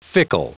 Prononciation du mot fickle en anglais (fichier audio)
Prononciation du mot : fickle